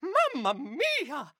Mario defeated from electrocution in Super Mario Bros. Wonder.
Mario_-_MamaMia_-_SMBW.oga.mp3